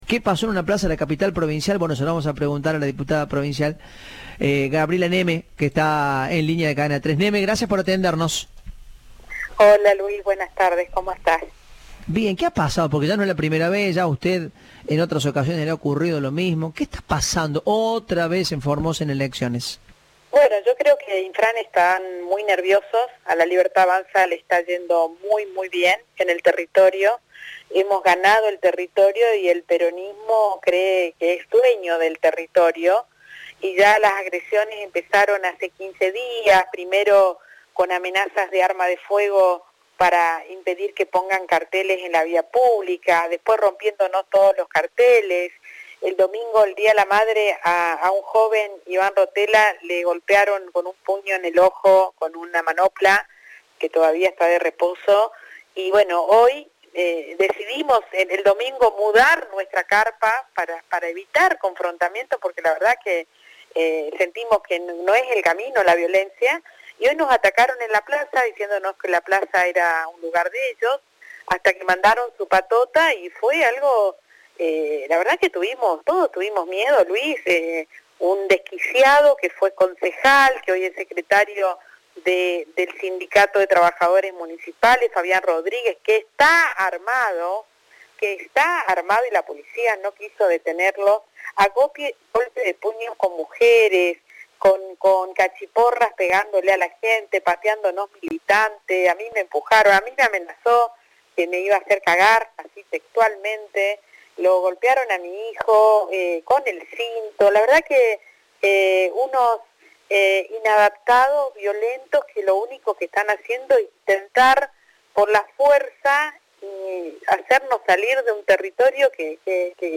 Entrevista de "Informados, al regreso".